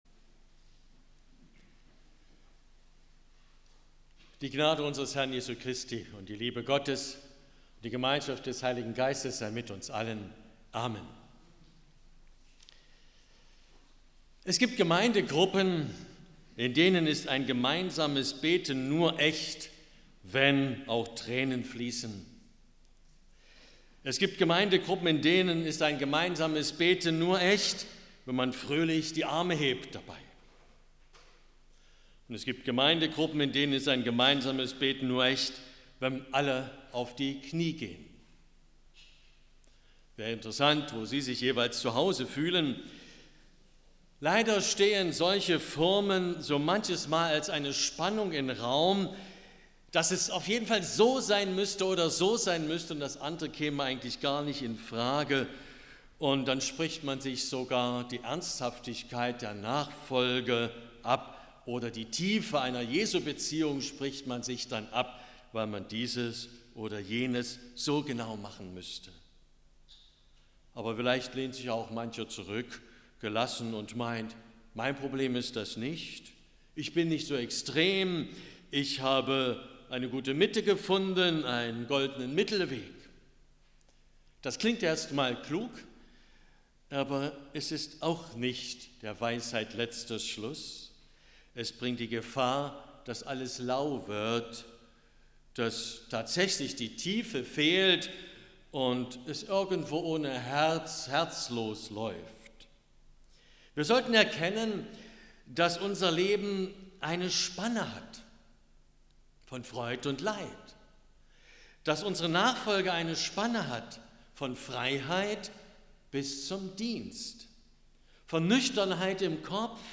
Predigt 19.02.2023